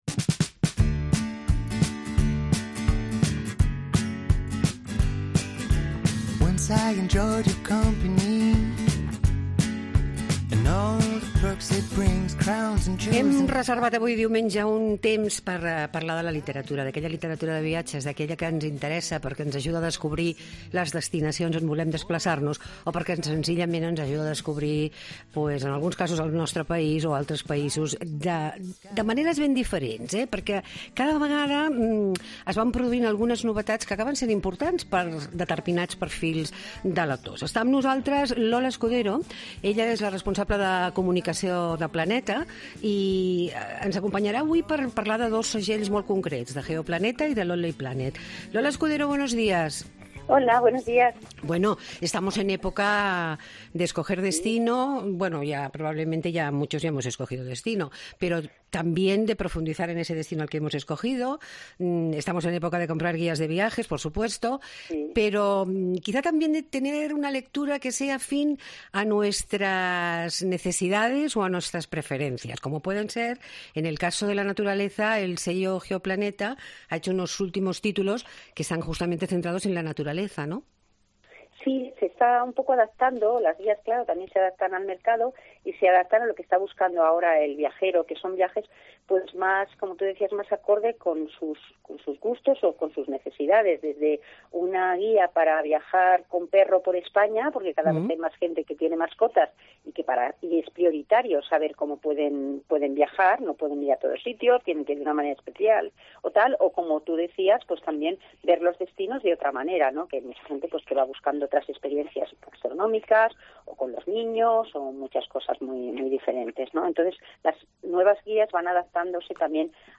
Todos los domingos hacemos una hora de radio pensada para aquellos que les gusta pasarlo bien en su tiempo de ocio ¿donde?